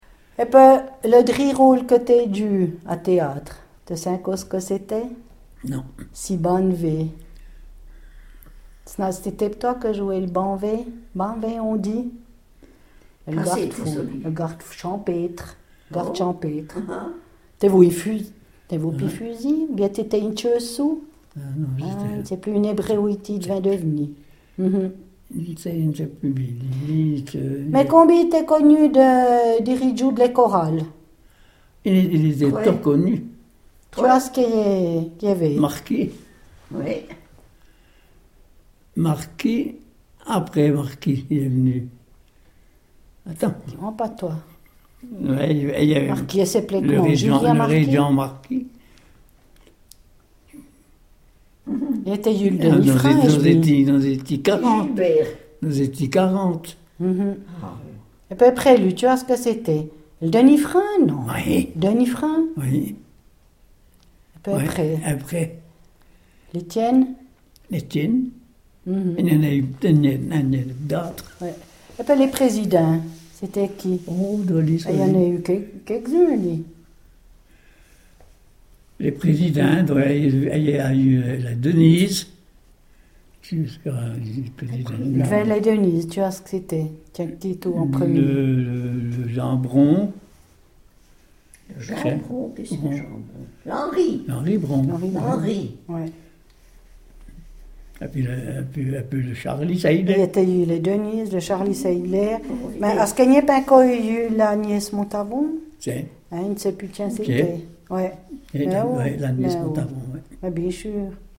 parlent le patois du Val Terbi.